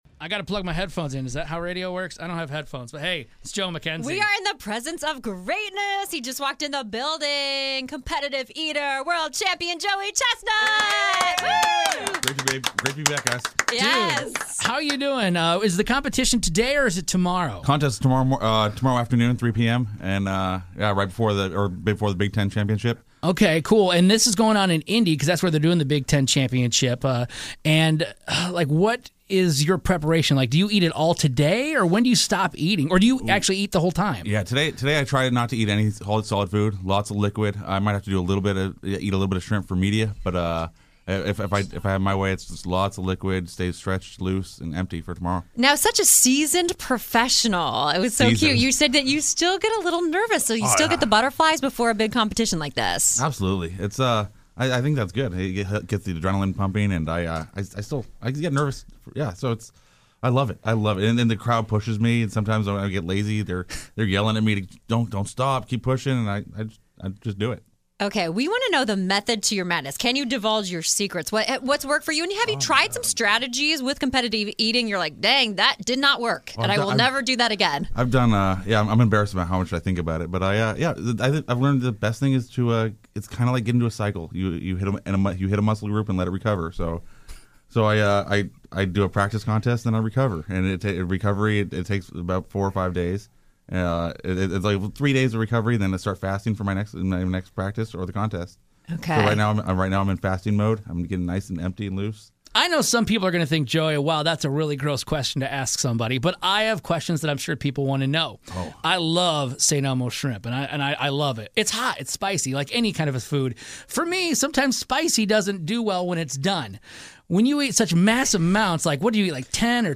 World Champion Competitive Eater Joey Chestnut was in the studio today.